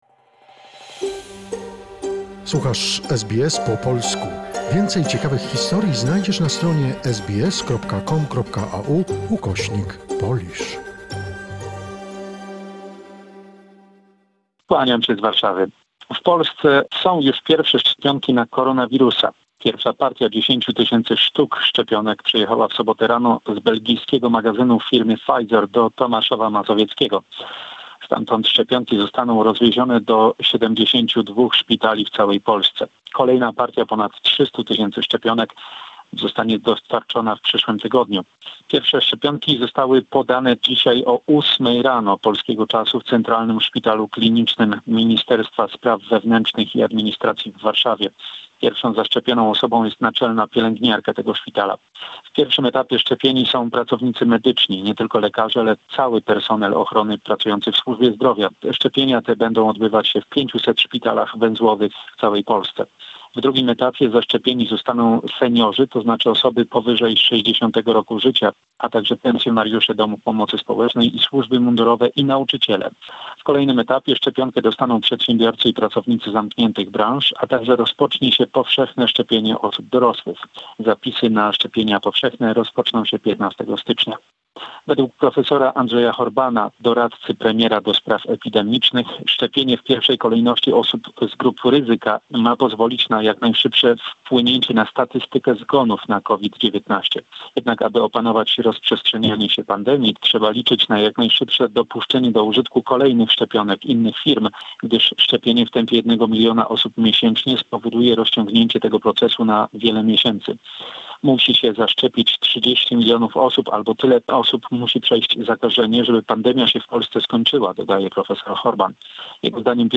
In a weekly report from Poland